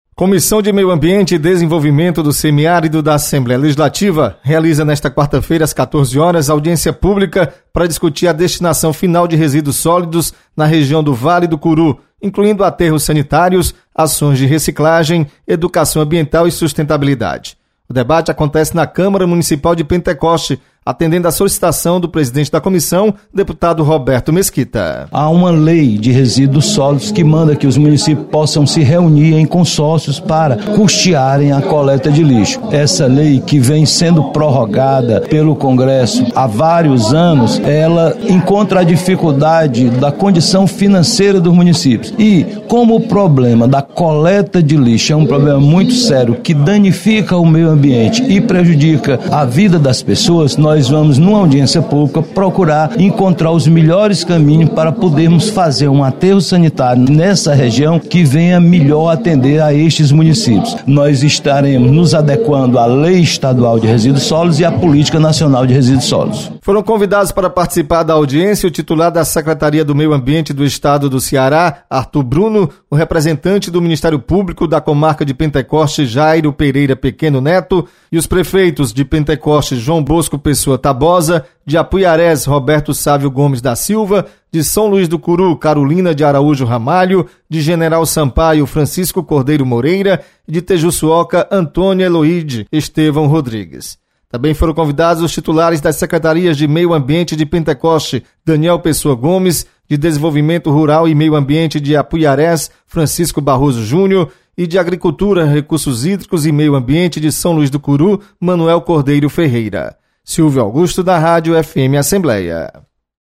Comissão de Meio Ambiente e Desenvolvimento do Semiárido realiza reunião nesta quarta-feira. Repórter